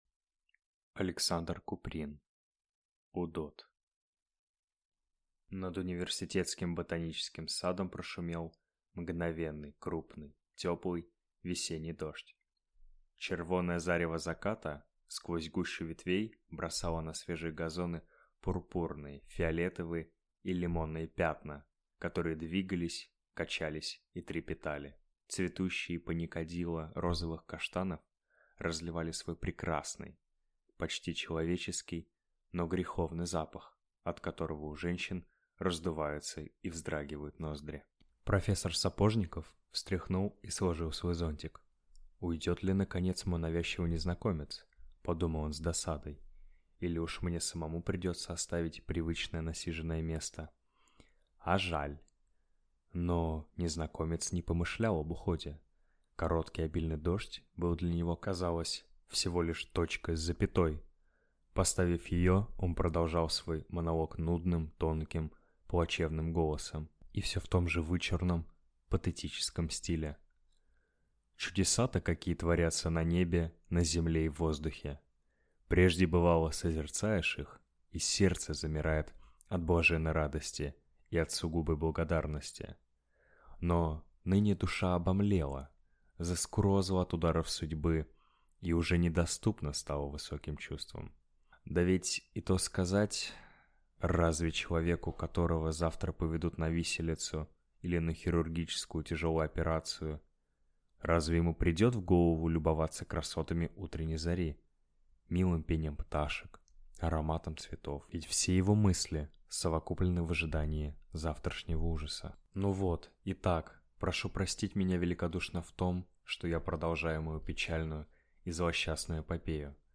Аудиокнига Удод | Библиотека аудиокниг